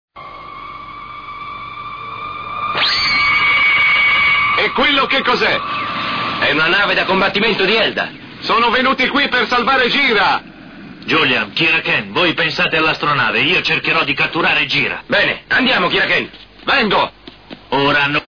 dal cartone animato "God Sigma, l'imperatore dello spazio"